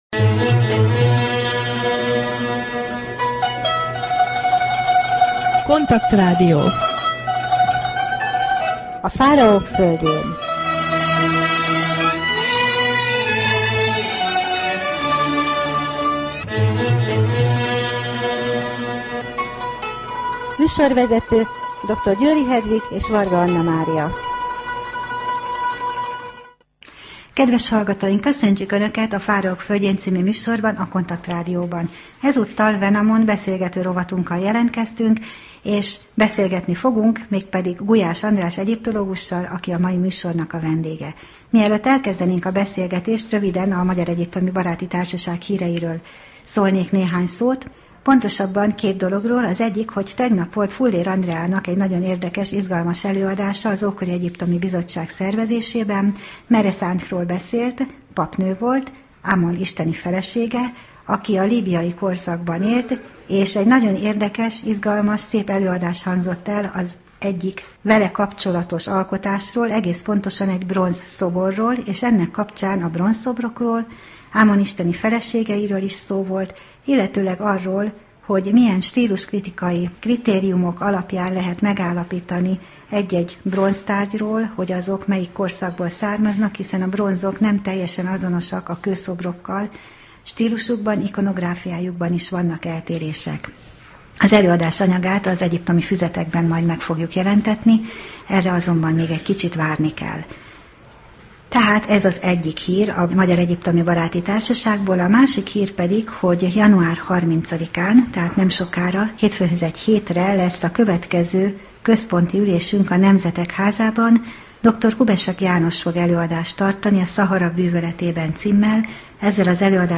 Wenamon beszélgető rovat